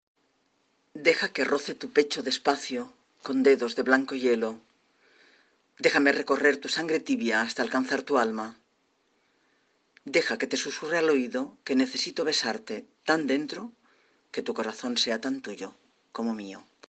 Me despido en este post con este poema con el que comencé la ponencia